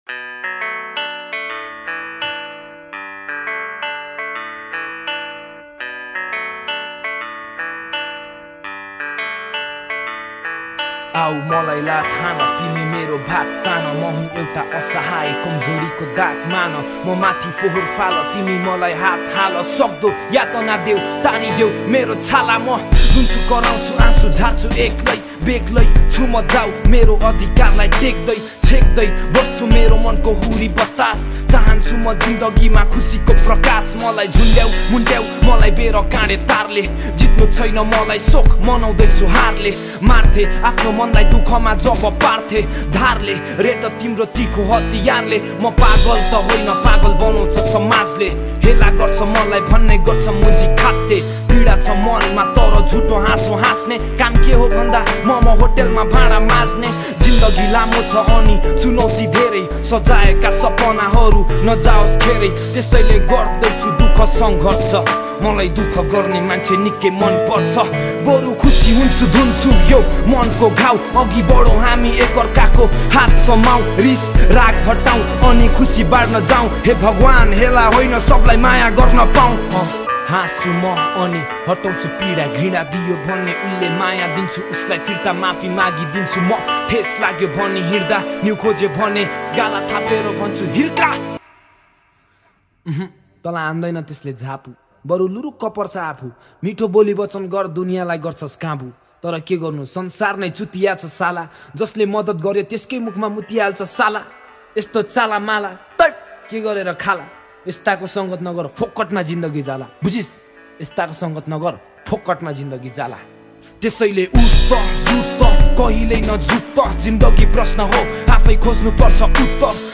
# Nepali Rap Mp3 Songs Download